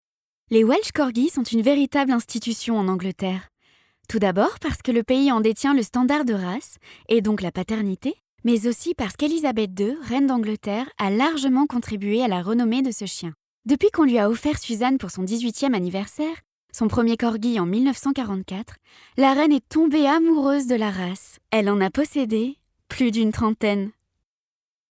Voix off
Documentaire
5 - 32 ans - Soprano